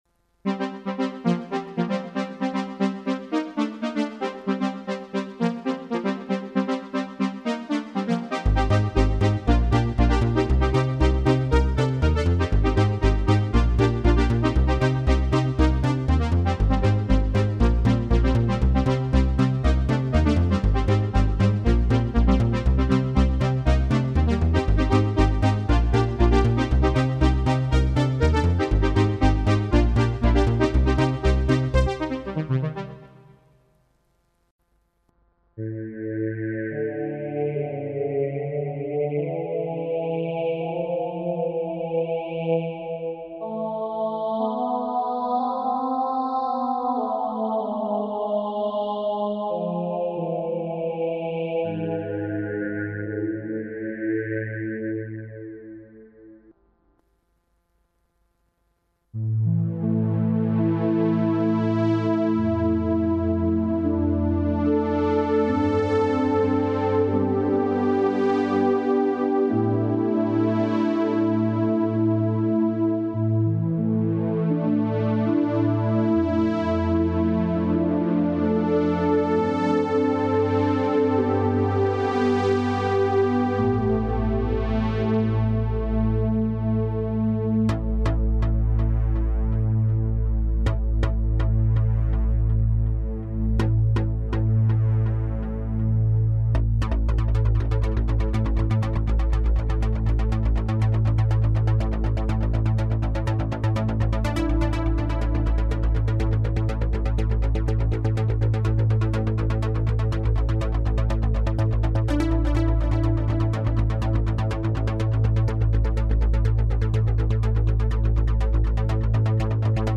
tc-nord stage demo suoni synth.mp3